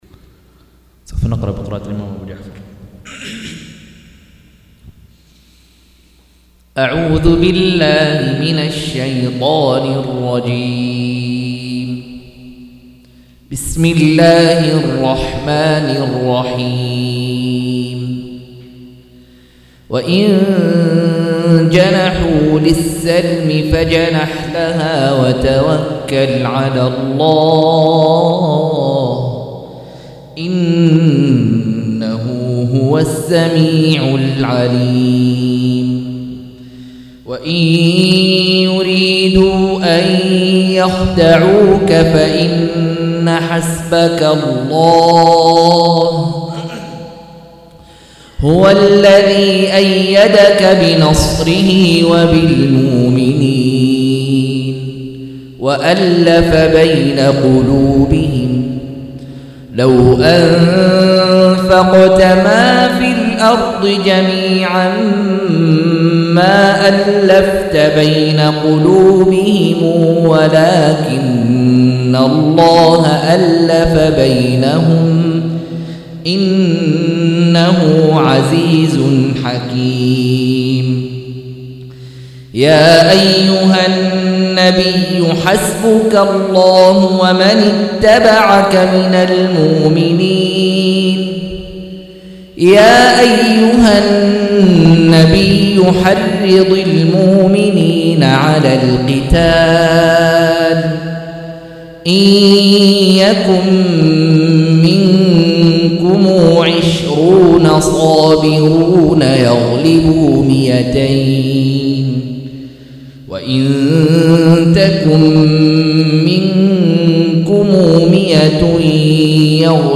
175- عمدة التفسير عن الحافظ ابن كثير رحمه الله للعلامة أحمد شاكر رحمه الله – قراءة وتعليق –